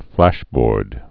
(flăshbôrd)